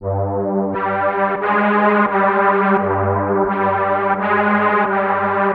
Yark Strings 01.wav